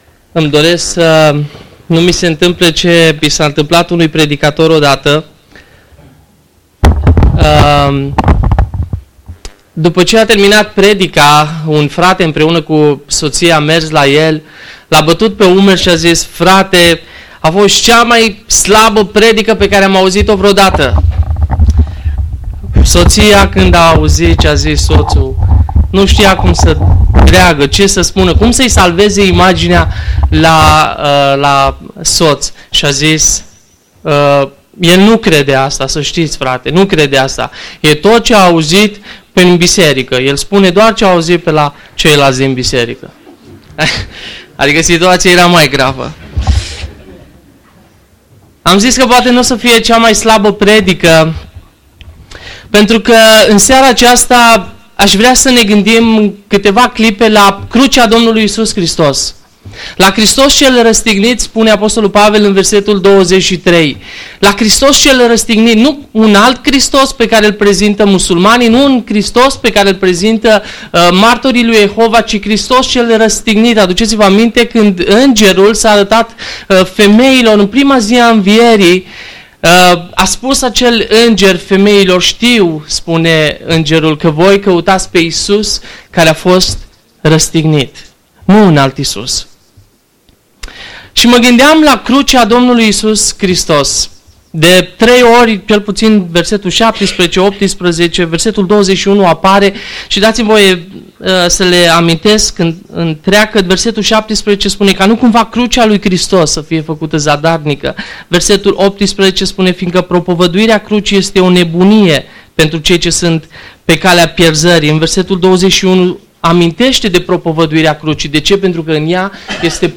Tineret, seara Predică, 1 Corinteni 1:1-31